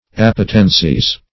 Appetencies - definition of Appetencies - synonyms, pronunciation, spelling from Free Dictionary
Appetency \Ap"pe*ten*cy\, n.; pl. Appetencies. [L. appetentia,